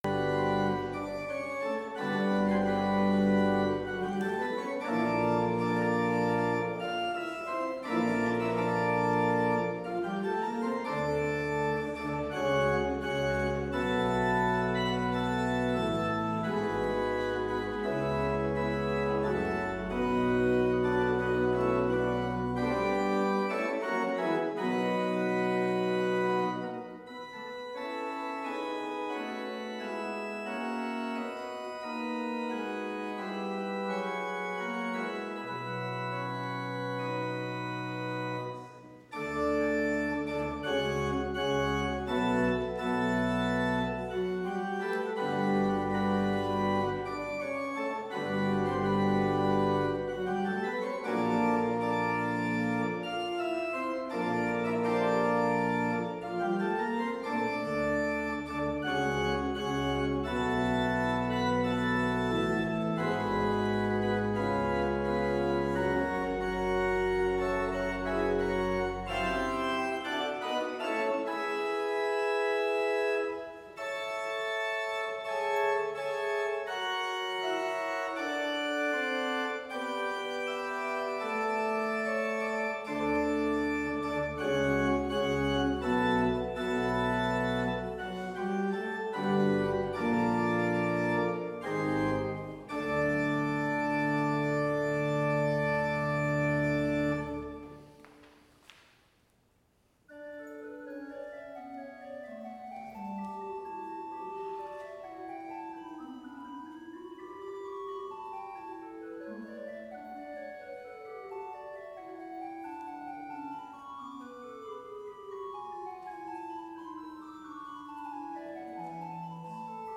Complete service audio for Chapel - June 7, 2023
Hymn 15 - Holy, Holy, Holy! Lord God Almighty